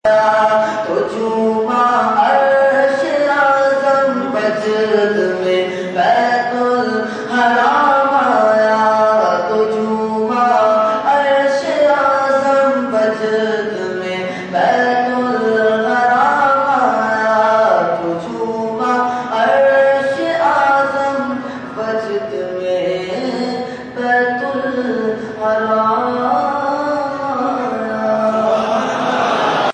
Category : Naat | Language : UrduEvent : Mehfil Milad Ghousia Masjid Liaqatabad 25 January 2014